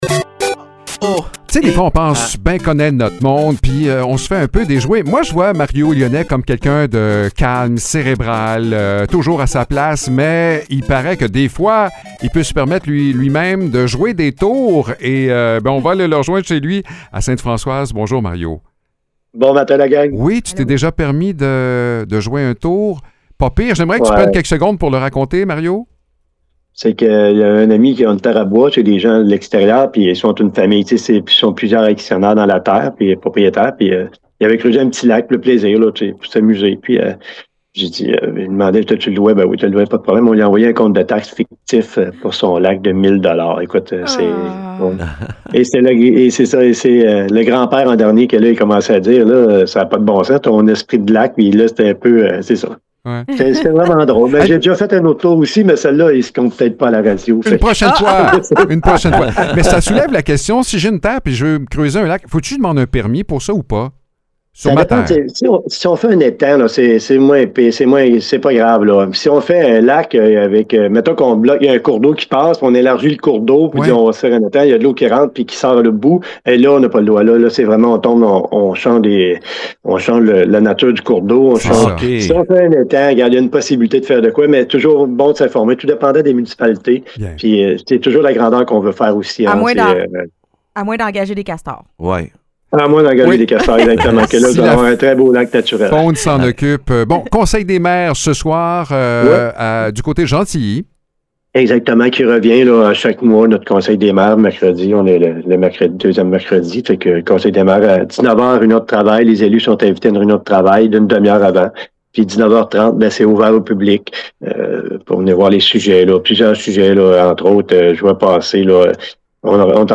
Mario Lyonnais, maire de Sainte-Françoise et préfet de la MRC de Bécancour, annonce que l’Union des producteurs agricoles (UPA) a mis sur pied une équipe d’effarouchement pour venir en aide aux citoyens aux prises avec les oies blanches et les bernaches envahissantes.